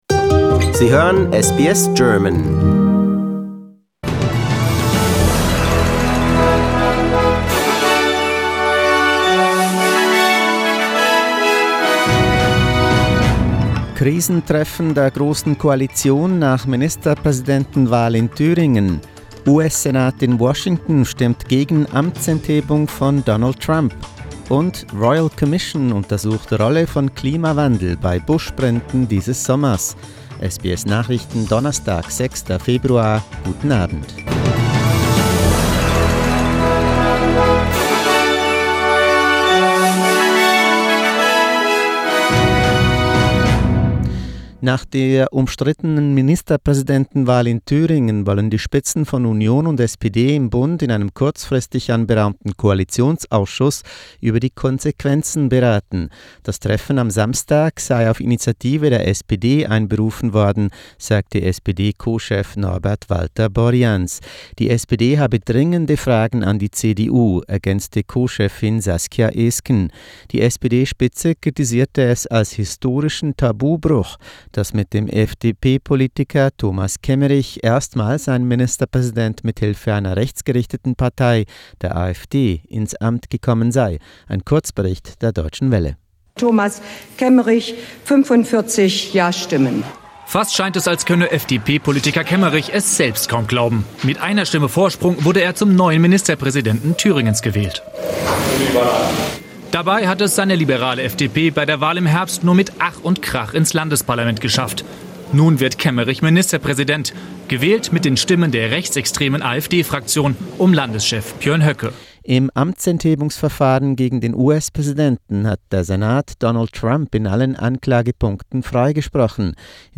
SBS Nachrichten, Donnerstag 6.2.20